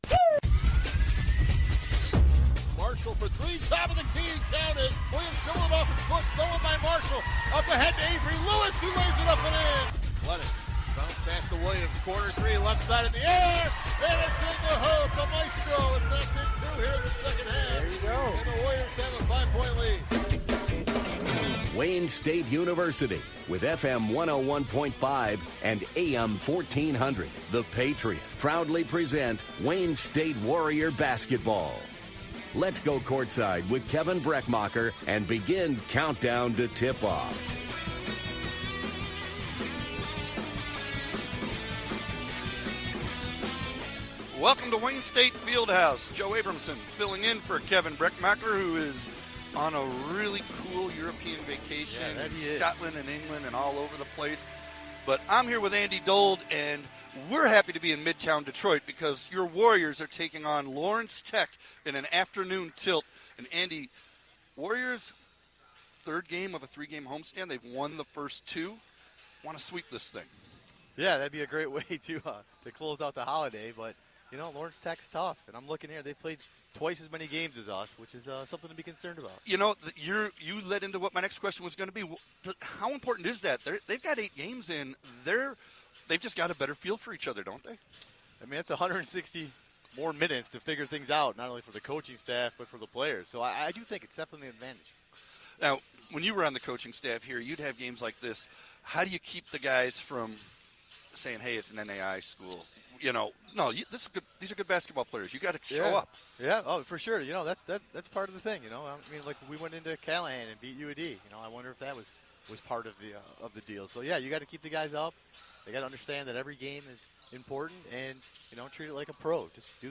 WDTK Broadcast of Men's Basketball vs. Lawrence Tech - Nov. 26, 2023